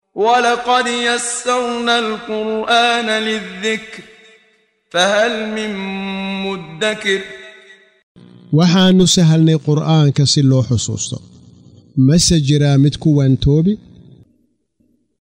Waa Akhrin Codeed Af Soomaali ah ee Macaanida Suuradda Al-Qamar ( Dayaxa ) oo u kala Qaybsan Aayado ahaan ayna la Socoto Akhrinta Qaariga Sheekh Muxammad Siddiiq Al-Manshaawi.